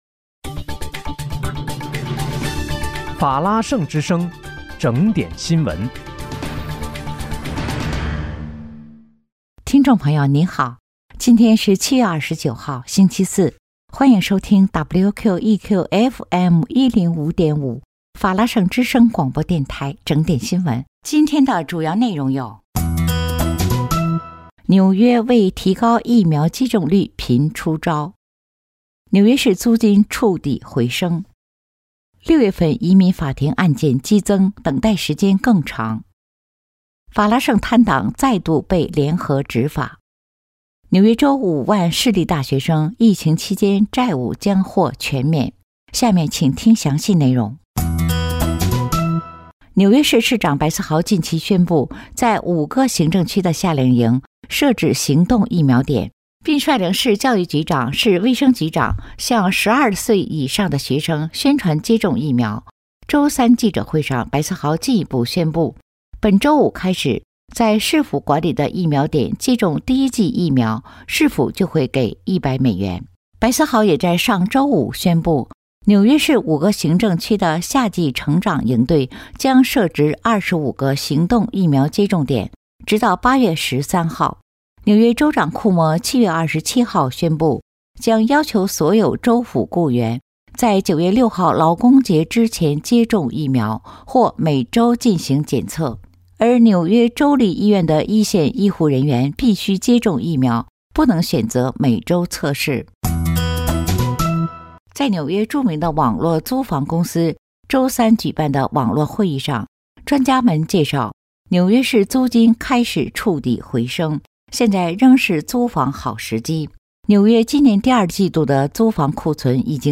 7月29日（星期四）纽约整点新闻
听众朋友您好！今天是7月29号，星期四，欢迎收听WQEQFM105.5法拉盛之声广播电台整点新闻。